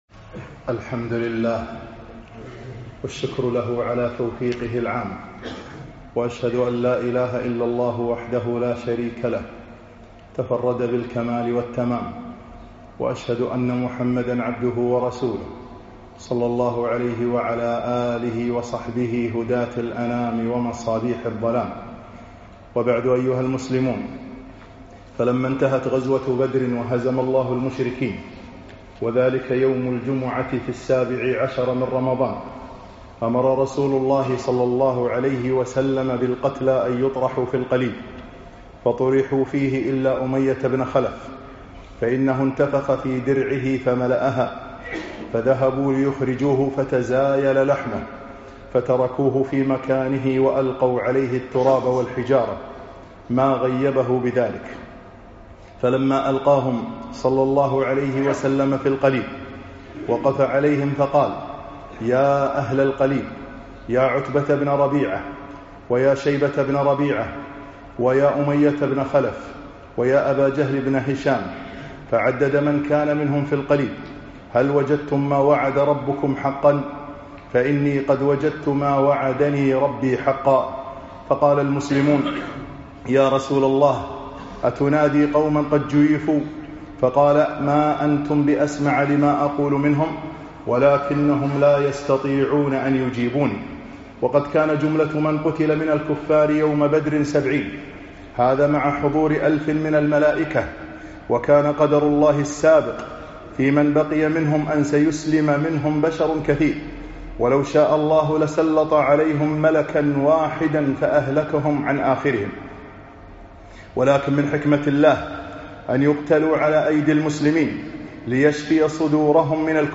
خطب السيرة النبوية 14